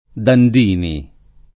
[ dand & ni ]